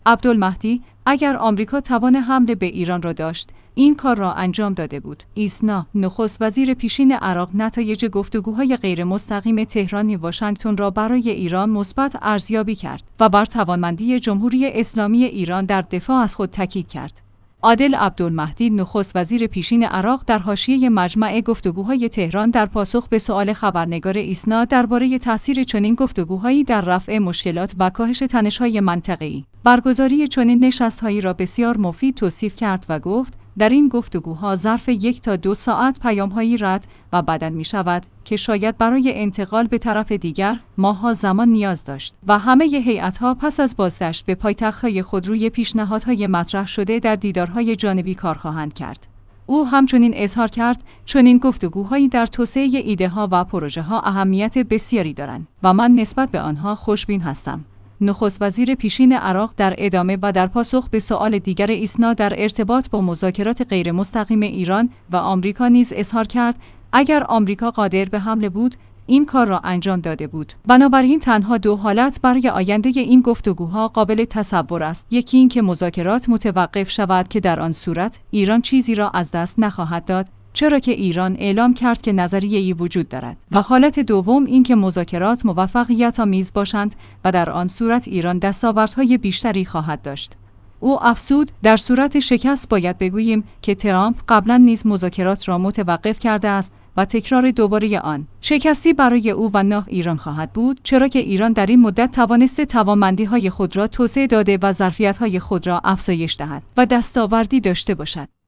ایسنا/ نخست‌وزیر پیشین عراق نتایج گفت‌وگوهای غیرمستقیم تهران-واشنگتن را برای ایران مثبت ارزیابی کرد و بر توانمندی جمهوری اسلامی ایران در دفاع از خود تاکید کرد. «عادل عبدالمهدی» نخست‌وزیر پیشین عراق در حاشیه مجمع گفت‌وگوهای تهران در پاسخ به سوال خبرنگار ایسنا درباره تاثیر چنین گفت‌وگوهایی در رفع مشکل